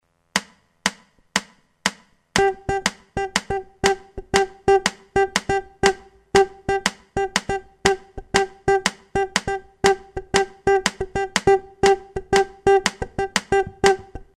Ogni CLAVE deriva in qualche modo dalla clave ABAKWA, di matrice africana, che si sviluppa su un tempo di sei ottavi